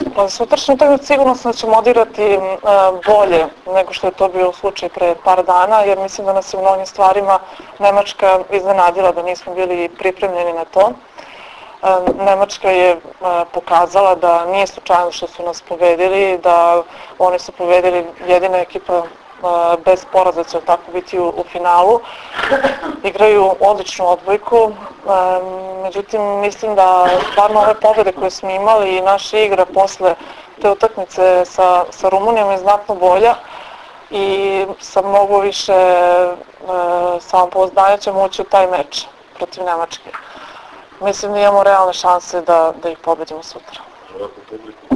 IZJAVA JELENE NIKOLIĆ 1